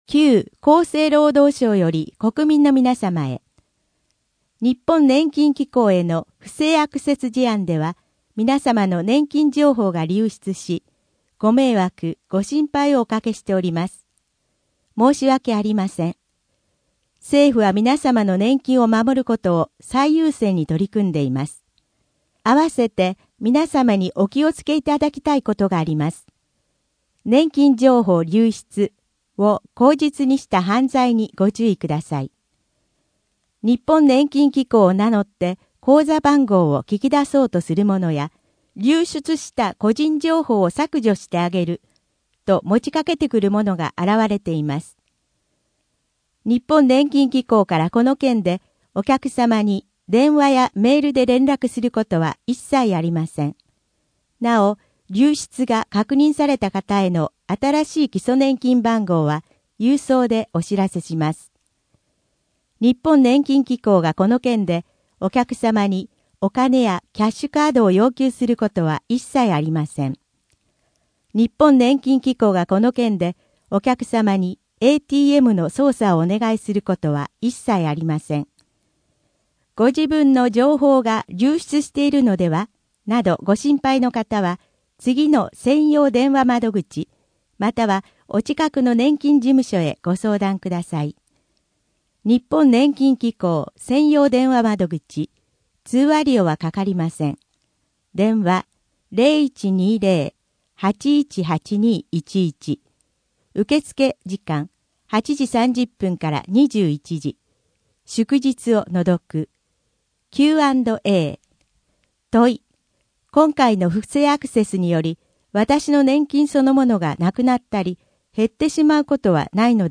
声の「広報はりま」9月号
声の「広報はりま」はボランティアグループ「のぎく」のご協力により作成されています。